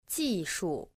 • jìshù